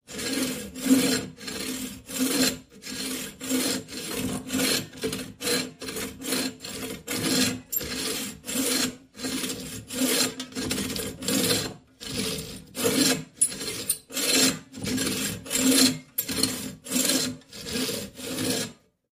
in_bowsaw_sawing_01_hpx
Bow saw squeaks while sawing hardwood. Tools, Hand Wood, Sawing Saw, Squeak